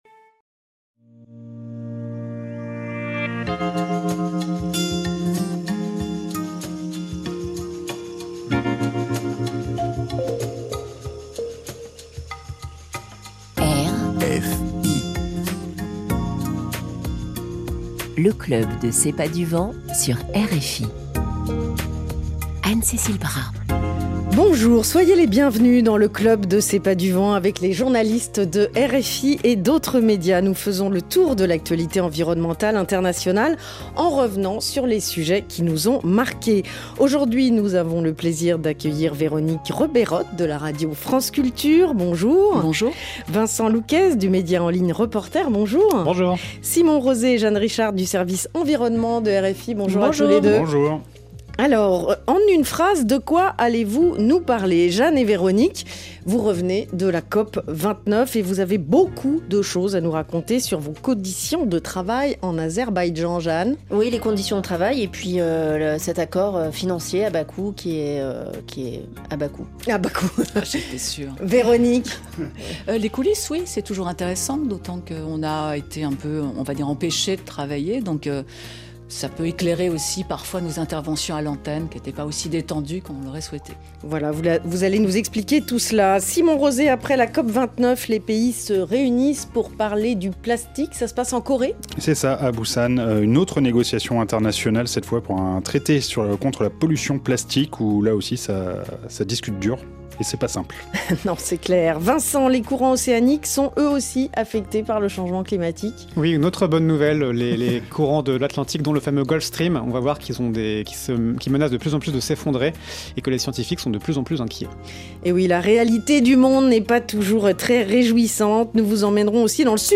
Des journalistes spécialistes de l'environnement, de RFI et d'ailleurs, reviennent sur les sujets environnementaux qui les ont marqués et partagent les coulisses de leur travail. L'occasion aussi de commenter les reportages produits par les vidéastes du réseau ePOP et les actions des Clubs RFI.